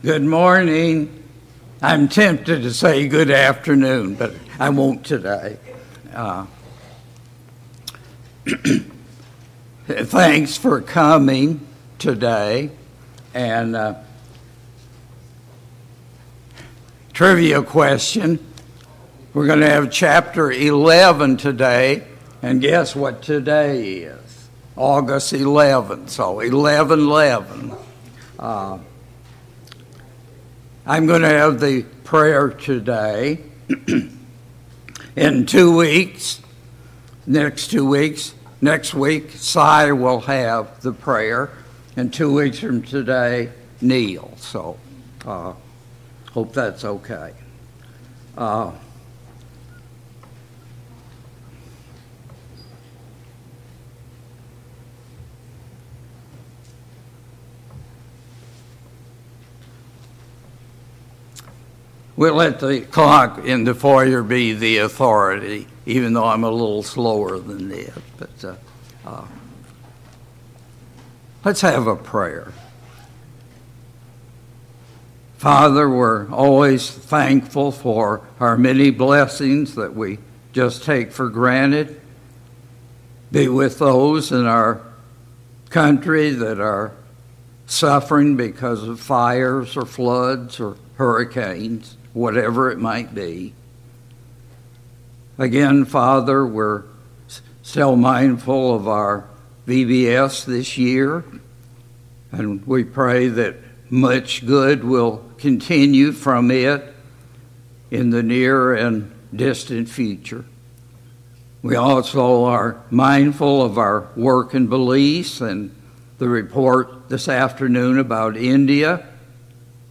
A Study of 2 Corinthians Passage: 2 Corinthians 11 Service Type: Sunday Morning Bible Class « 19.